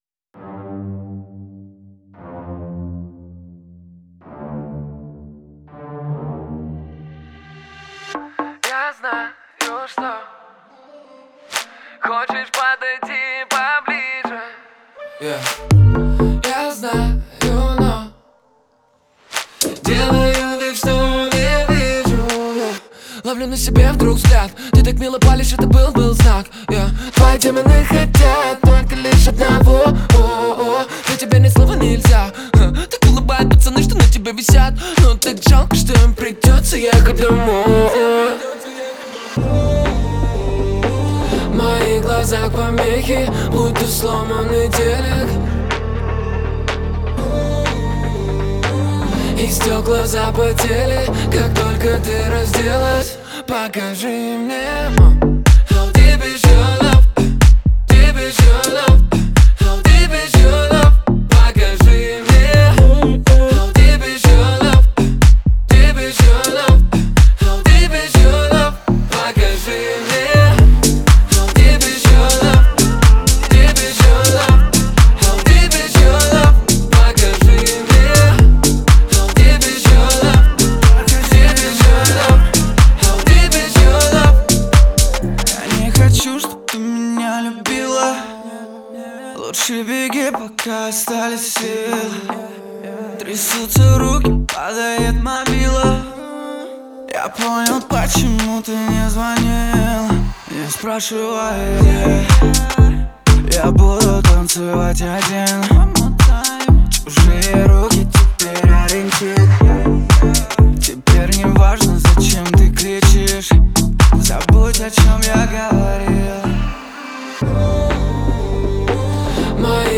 это яркий трек в жанре хип-хоп/рэп